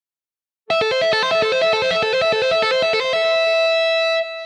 Гитарное упражнение 1
Аудио (100 УВМ)